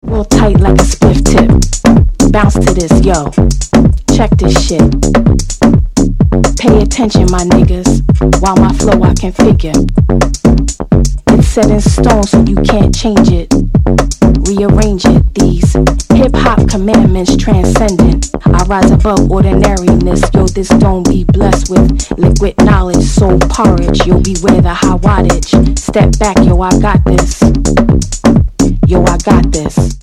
with some nice edgy and slick bass tone driven tech business